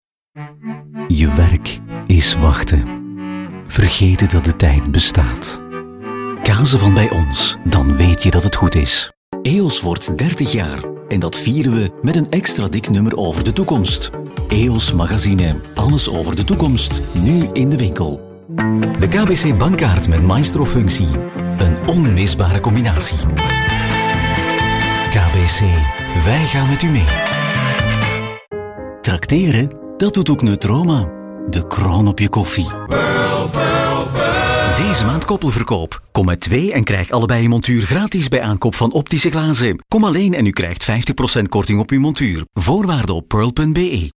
Comercial, Profundo, Seguro, Cálida, Empresarial
Comercial